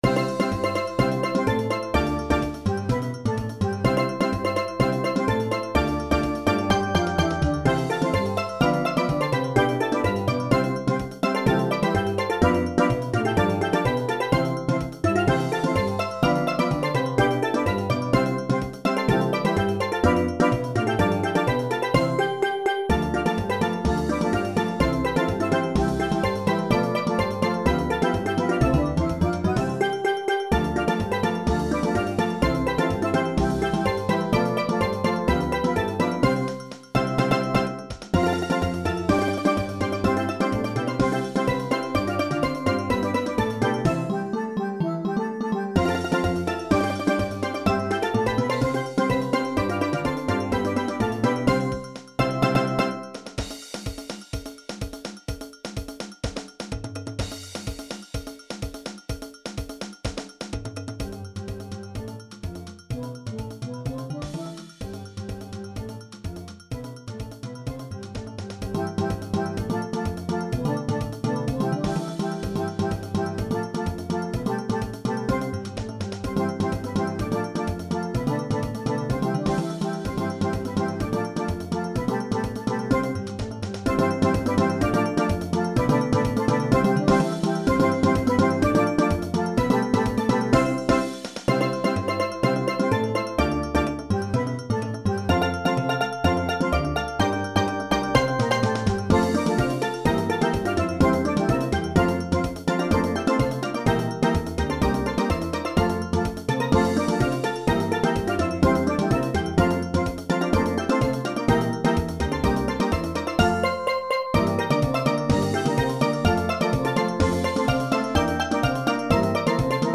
Genre: Steel Band
Lead/Tenor
Double Tenors
Guitar/Cello
Tenor-Bass
Bass
Drum Set
Engine Room 1: Cowbell, Metal Güiro
Engine Room 2: Brake Drum
written for steel pan ensemble in the style of early Calypso